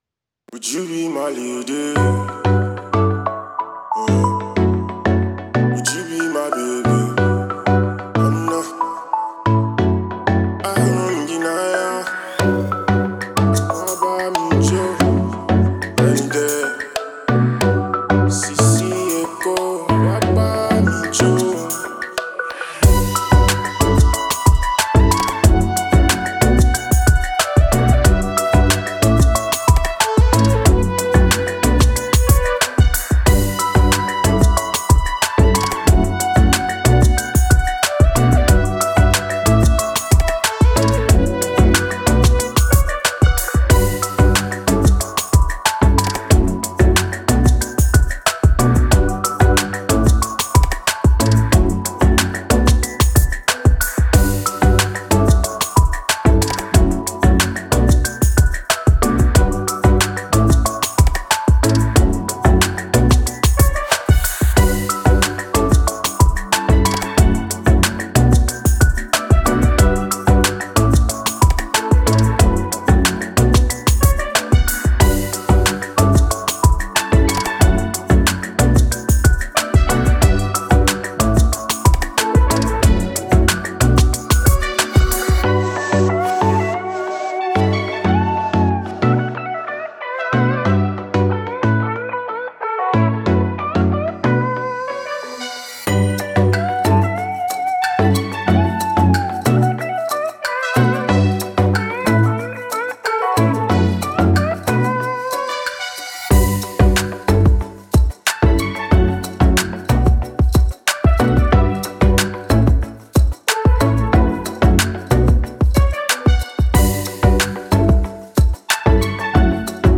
Genre: electronic, pop.